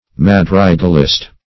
Search Result for " madrigalist" : Wordnet 3.0 NOUN (1) 1. a singer of madrigals ; The Collaborative International Dictionary of English v.0.48: Madrigalist \Mad"ri*gal*ist\, n. A composer of madrigals.